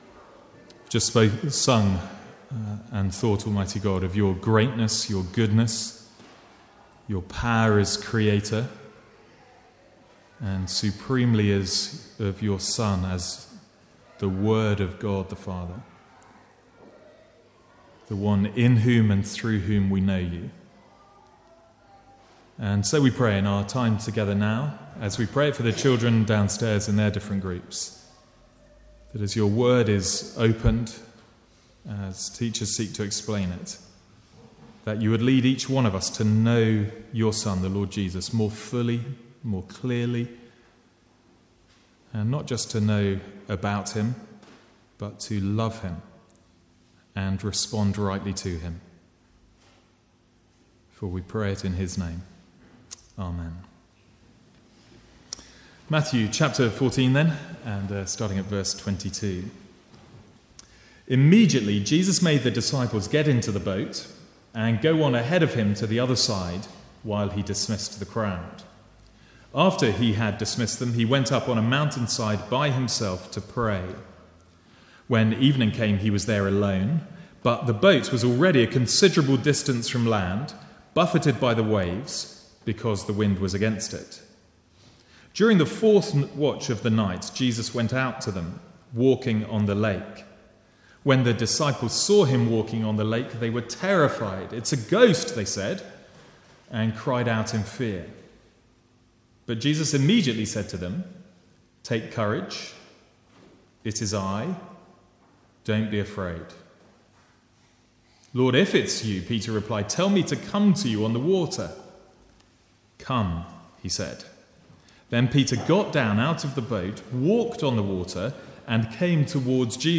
Sermons | St Andrews Free Church
From the Sunday morning series in Matthew.